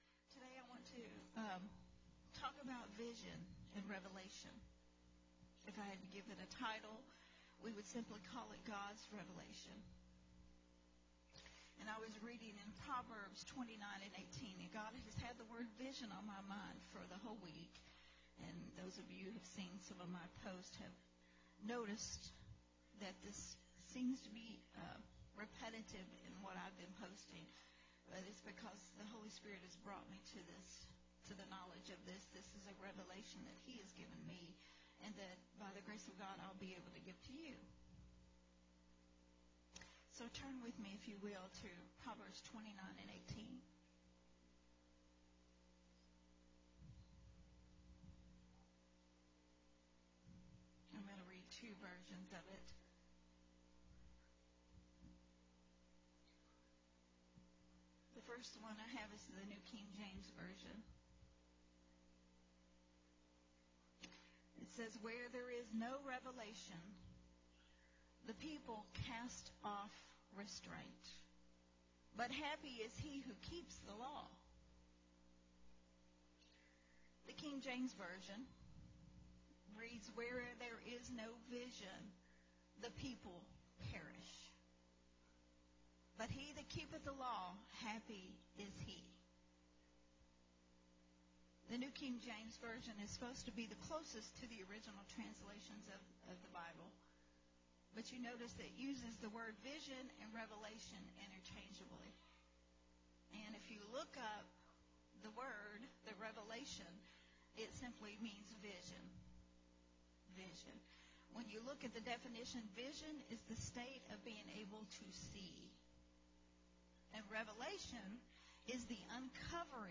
a teaching
recorded at Unity Worship Center on June 12